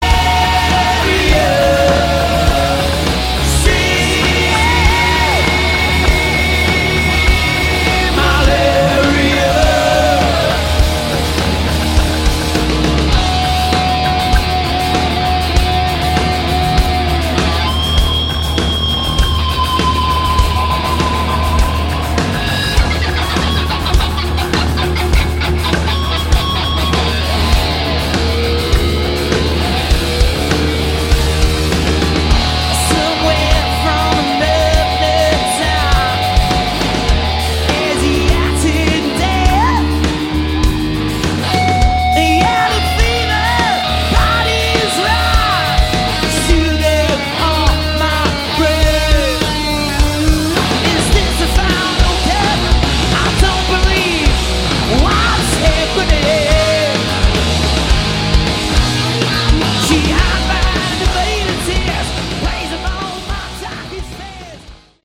Category: Sleazy Hard rock
guitar
vocals
drums
bass